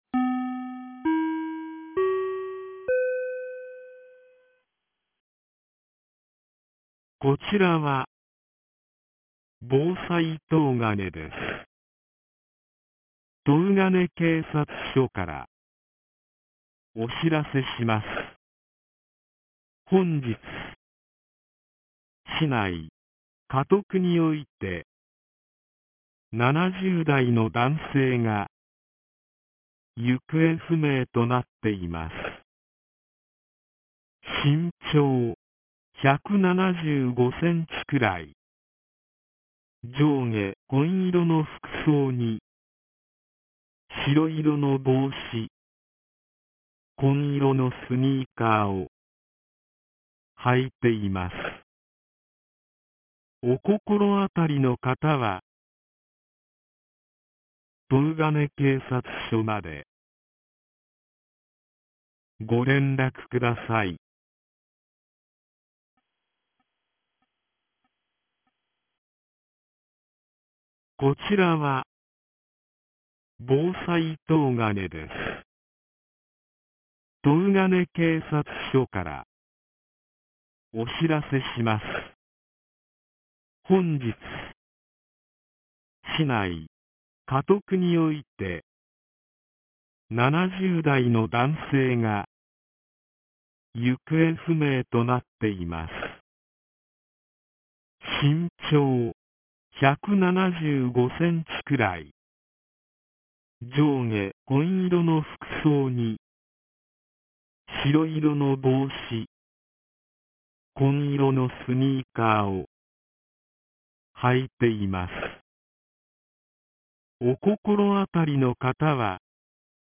2025年10月23日 16時26分に、東金市より防災行政無線の放送を行いました。